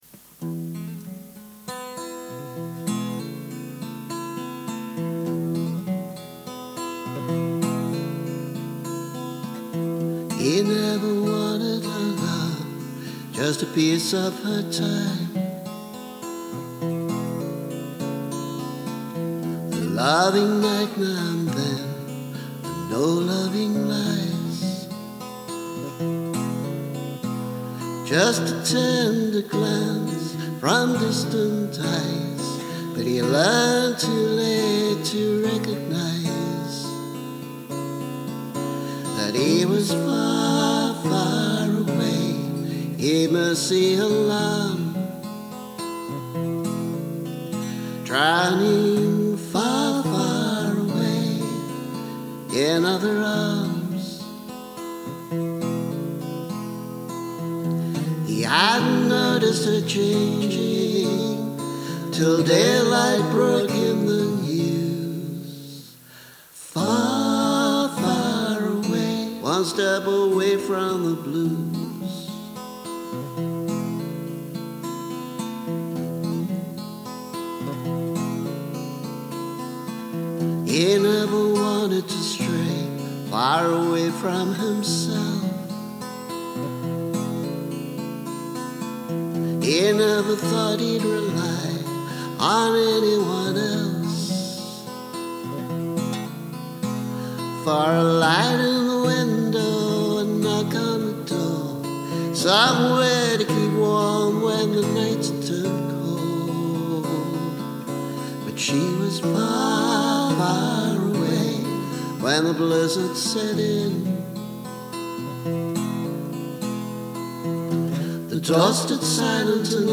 However, here’s a more recent demo version – just acoustic guitar and double tracked vocal.